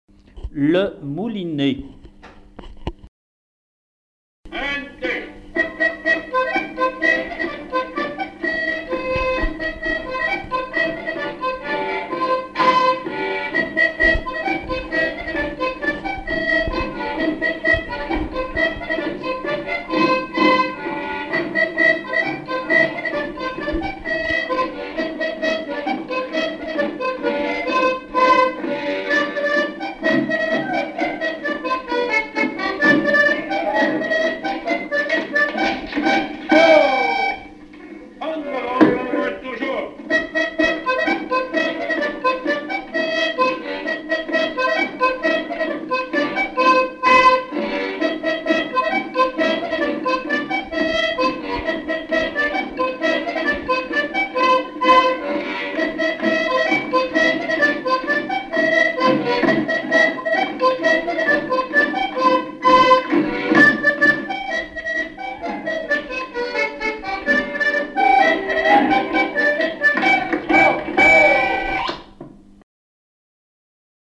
danse : quadrille : moulinet
Pièce musicale inédite